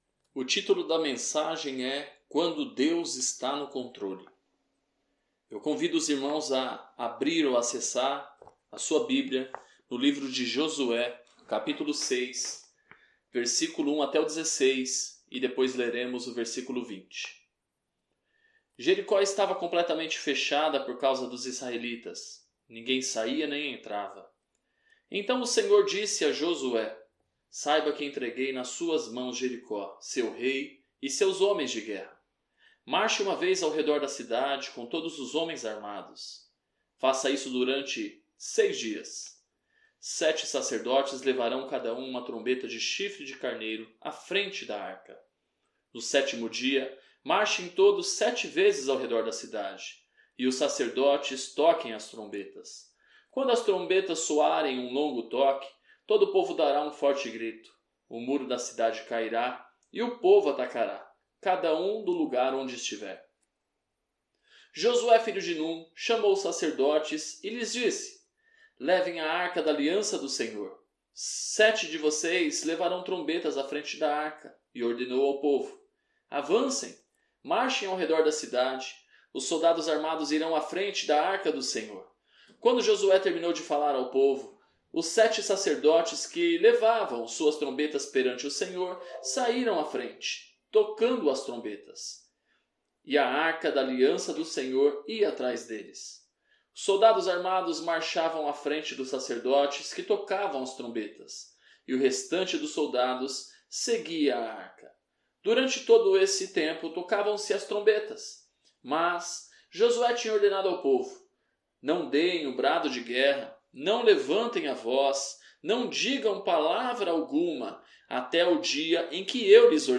Este episódio está com algumas mudanças de áudio devido a problemas técnicos.